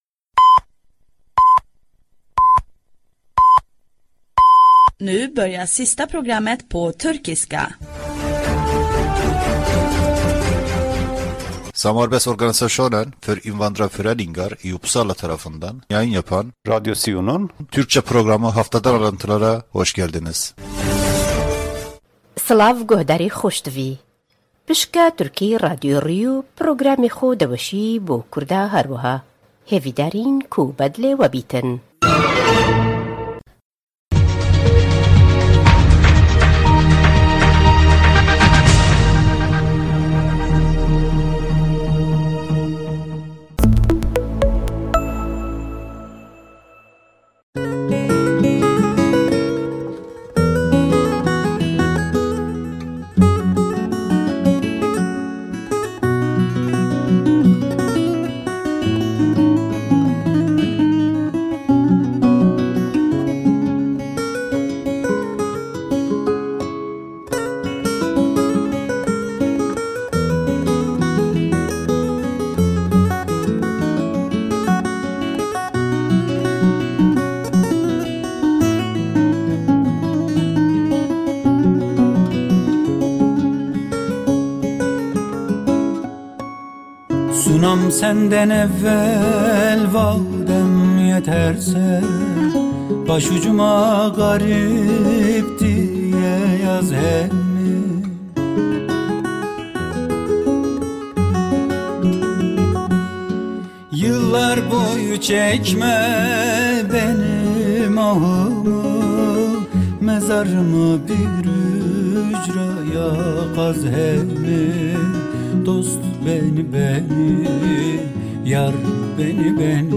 Radio SIU Türkçe programı.